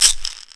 Perc (Quarter Milli - Offset).wav